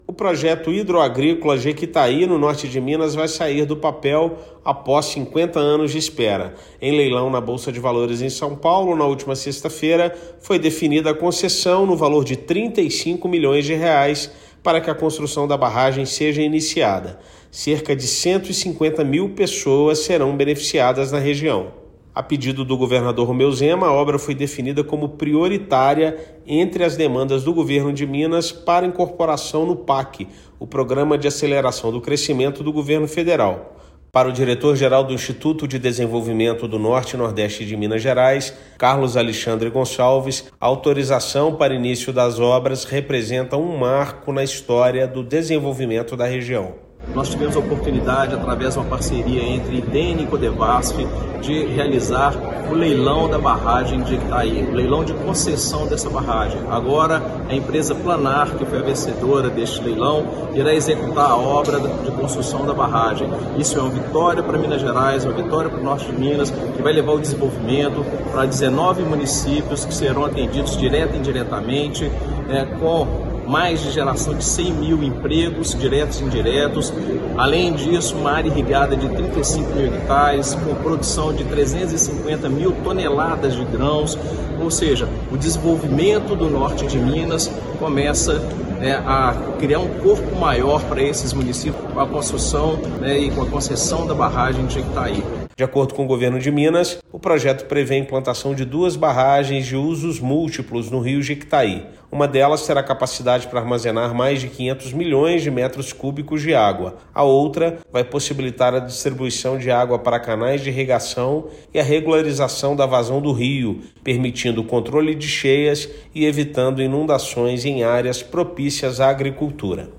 Concessão para as intervenções representa marco histórico para o desenvolvimento econômico e social do Norte de Minas. Ouça matéria de rádio.